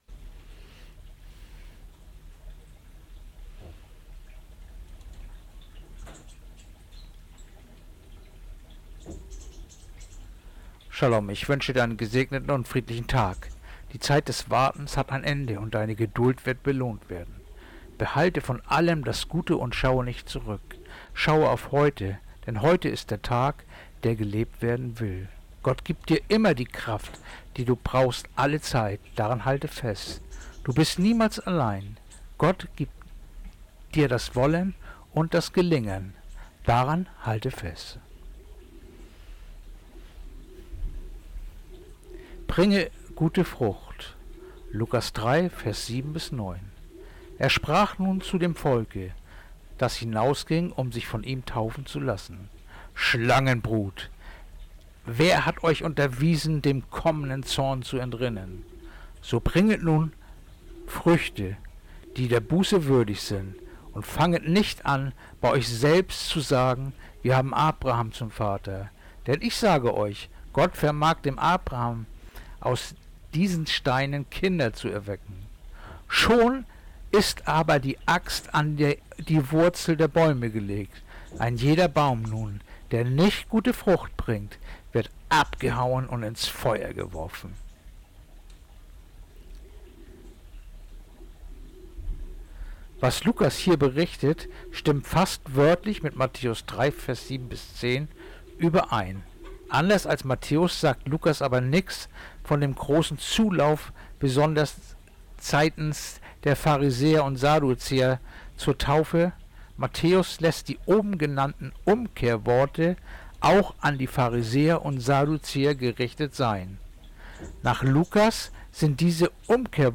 Andacht-vom-08.-Dezember-Lukas-3-7-9.mp3